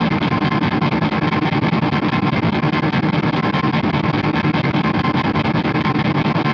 Future_engine_7_on.wav